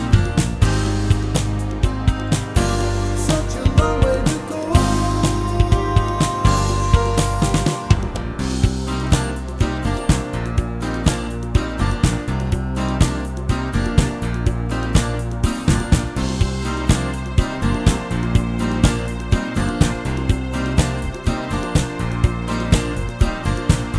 no Backing Vocals Soft Rock 4:16 Buy £1.50